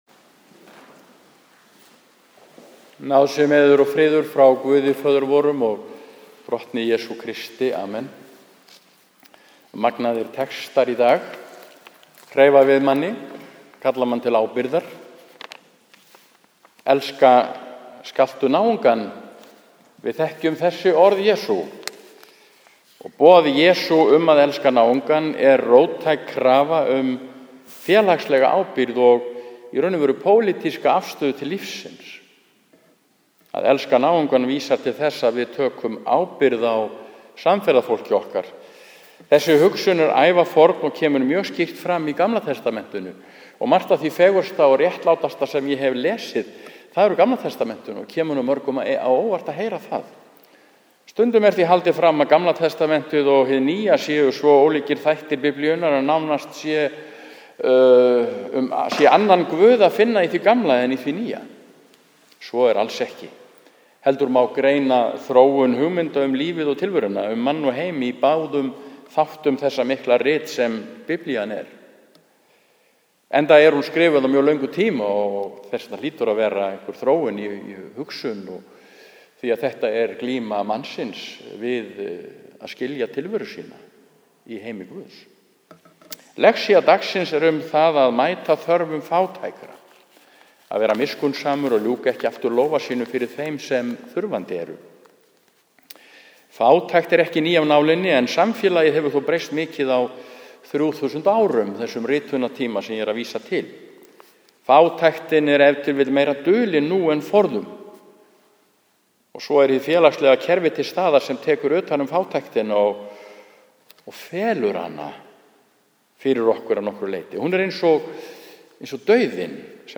Prédikun